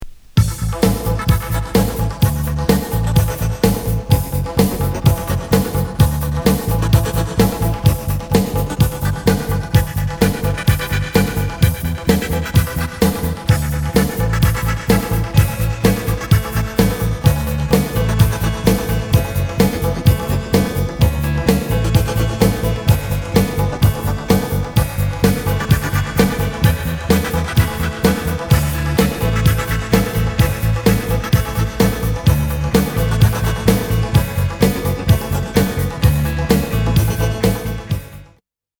かなり嬉しいエクステンデッドMIX。
後半エレクトロ・ロッキン・ダビーなインスト付き。